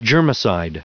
Prononciation du mot germicide en anglais (fichier audio)
Prononciation du mot : germicide